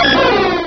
Cri de Celebi dans Pokémon Rubis et Saphir.